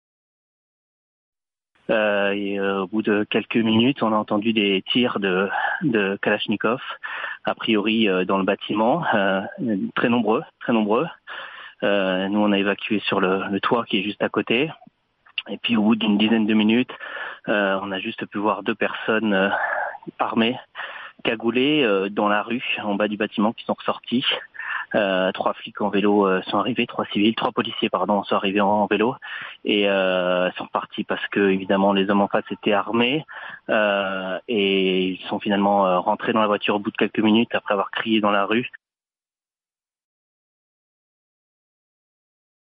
Une voisine des bureaux de Charlie Hebdo.mp3